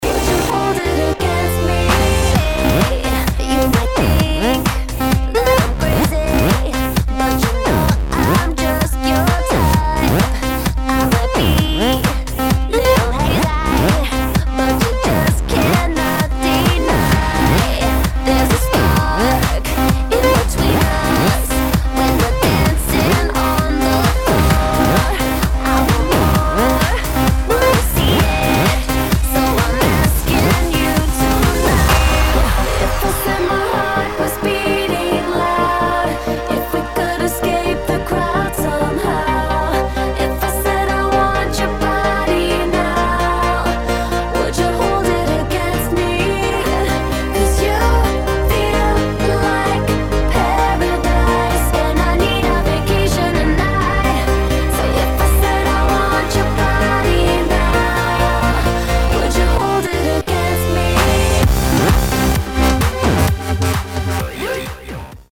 [ POP | HOUSE | DUBSTEP ]